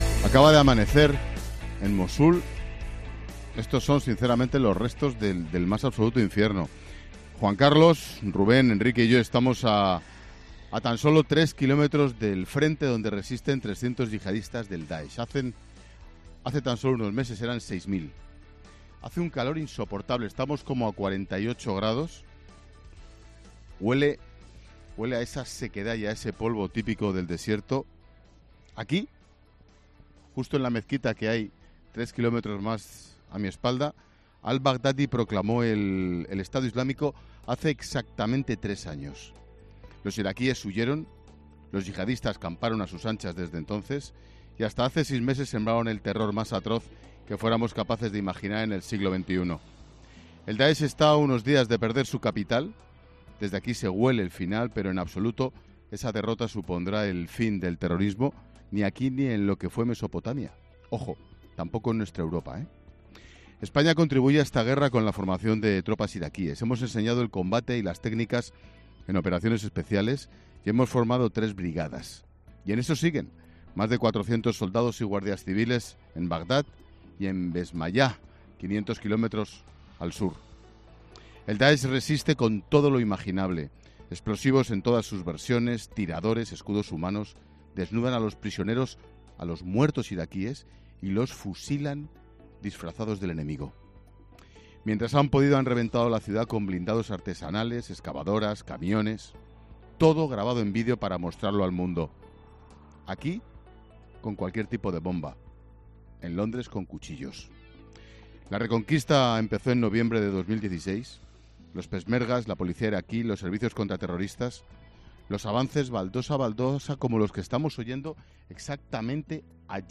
Monólogo de Expósito
Monólogo de Ángel Expósito a las 16h. desde Mosul, Irak, contando en qué punto está la reconquista de la que ha sido capital del autodenominado Estado Islámico.